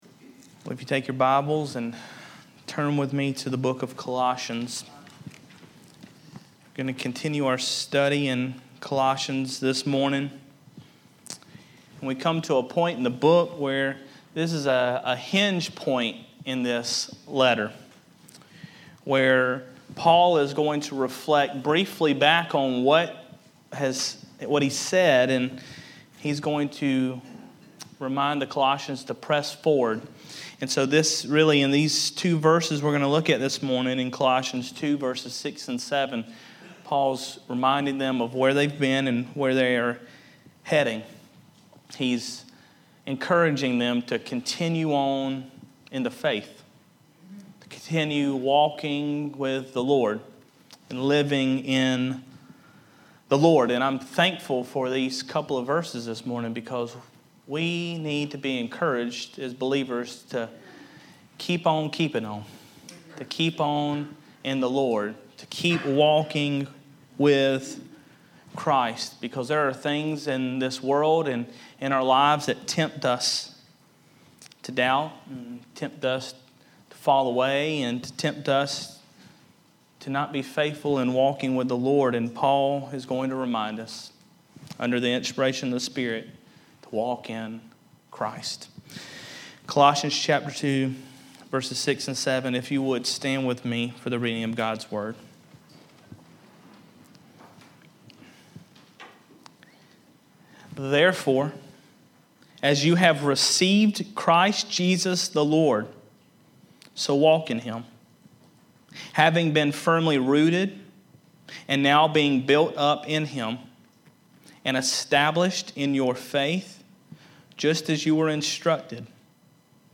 Sunday Morning Worship